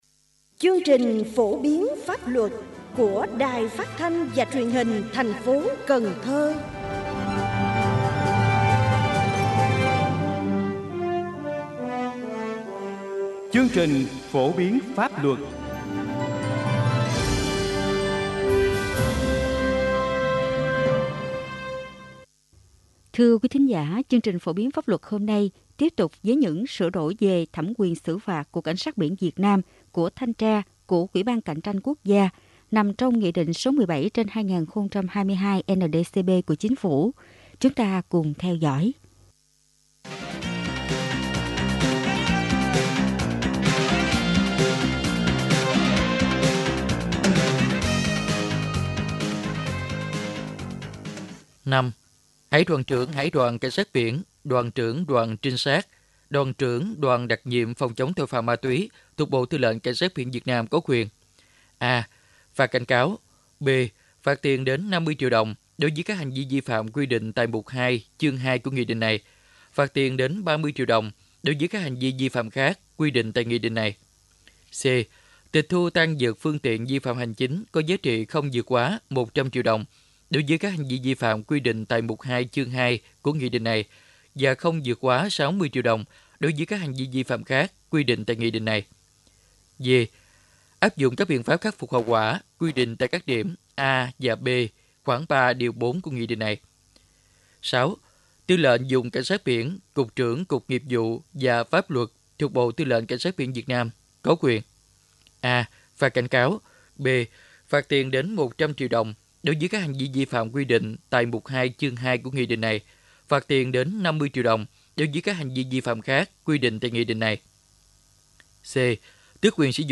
Mời quý thính giả nghe chương trình Phổ biến pháp luật của Đài Phát thanh và Truyền hình thành phố Cần Thơ.